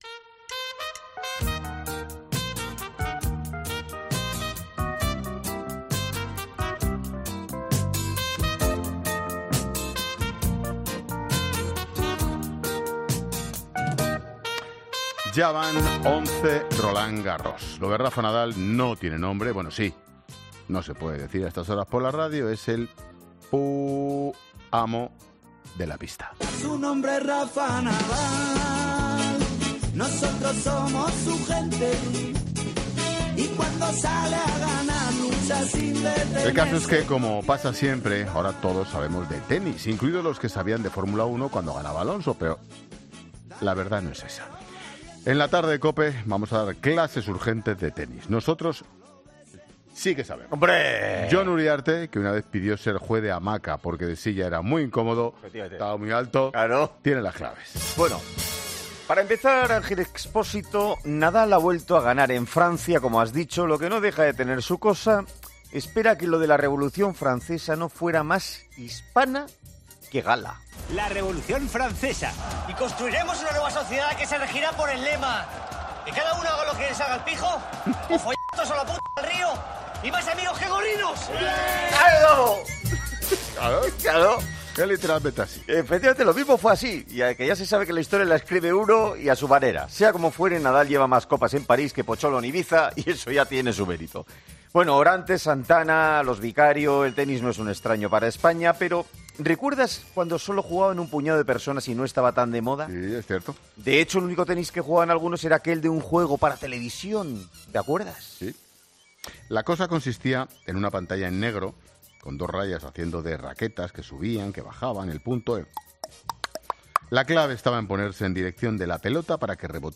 es un magazine de tarde que se emite en COPE